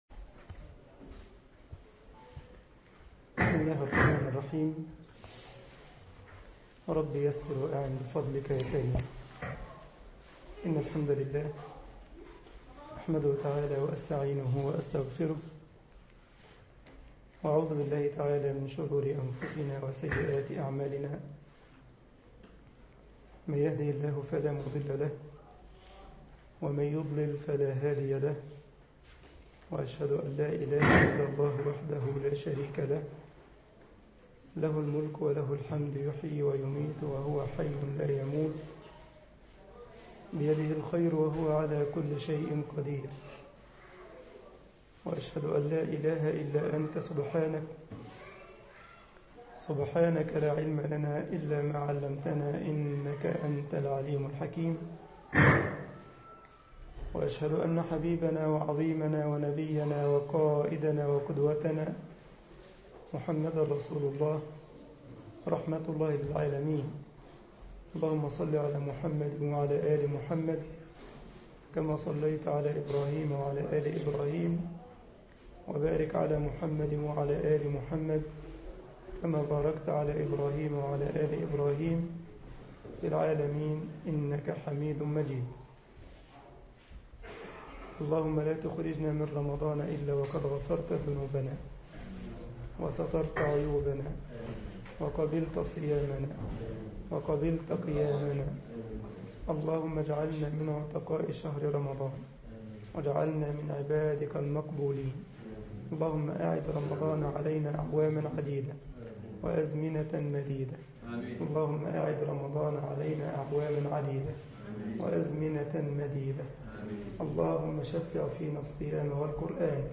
مسجد مدينة كايزرسلاوترن ـ ألمانيا درس